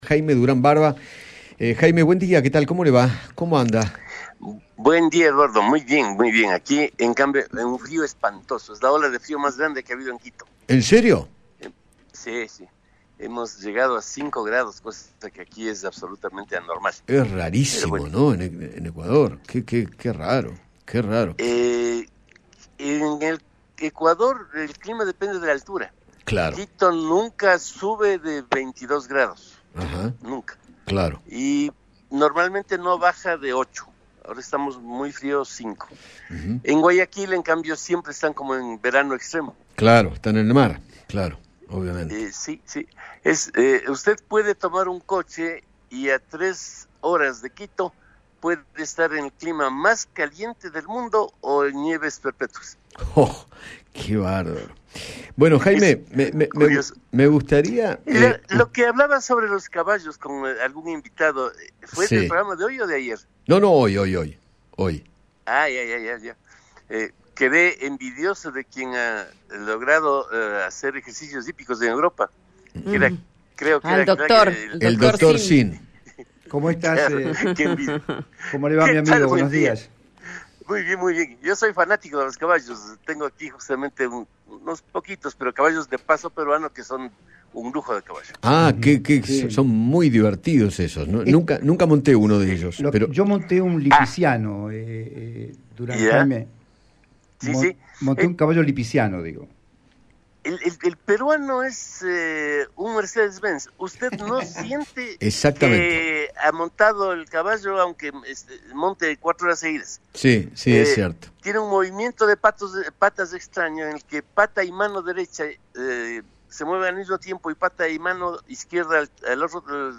Jaime Durán Barba, asesor político, dialogó con Eduardo Feinmann acerca de cómo ven a la Argentina en otros países e hizo un análisis político, social y económico.